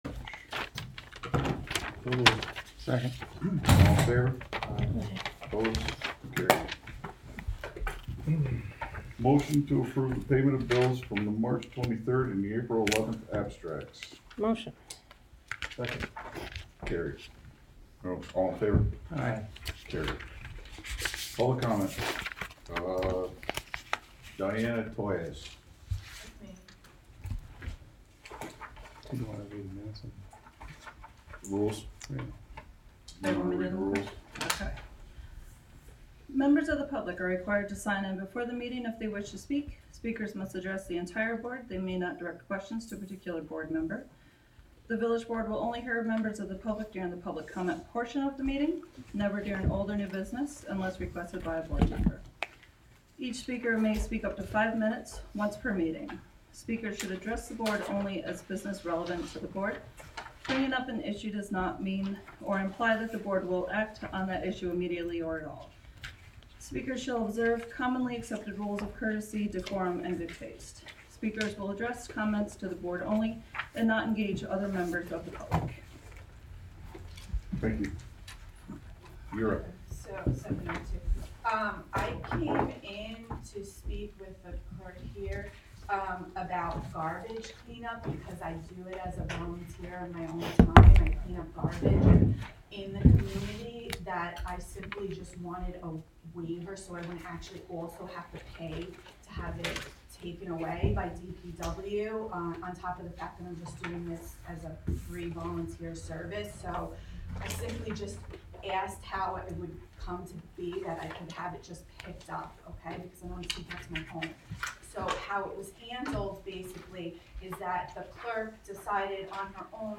Live from the Village of Philmont: Village Board Meeting (Audio)
Live from the Village of Philmont: Village Board Meeting (Audio) Apr 11, 2022 shows Live from the Village of Philmont Live stream of the Village of Philmont public meetings.